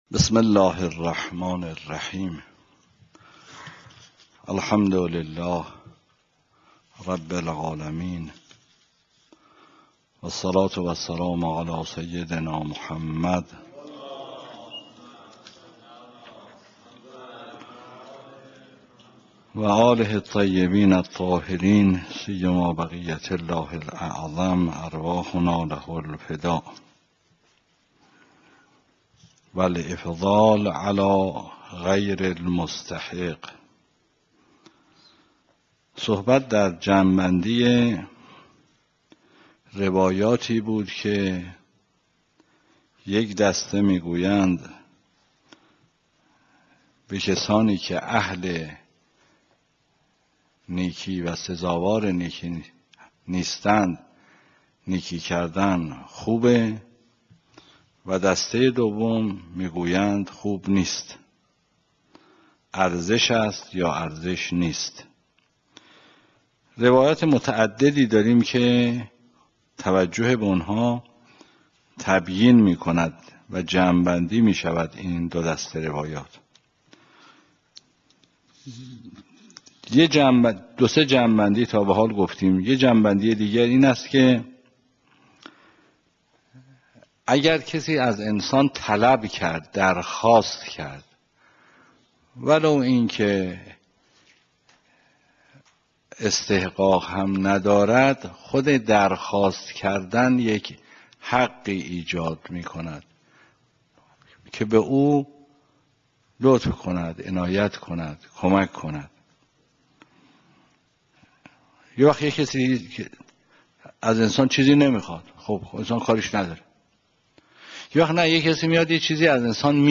درس خارج فقه